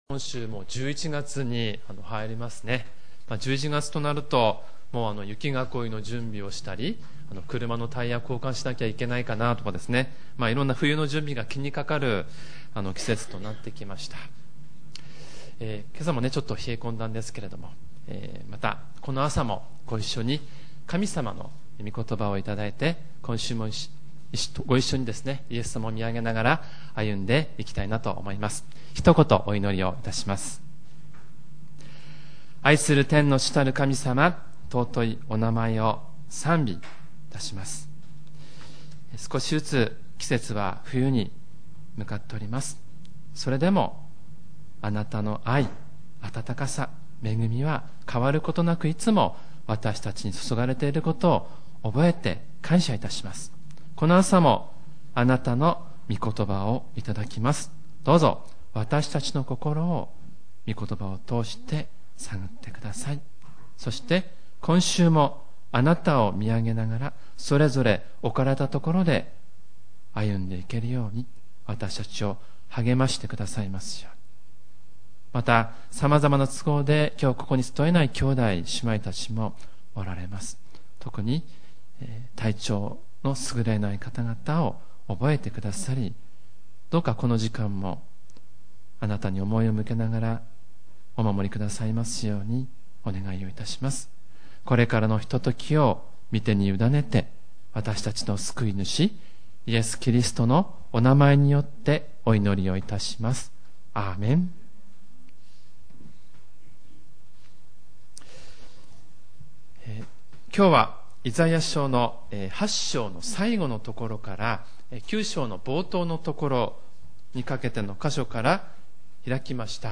●主日礼拝メッセージ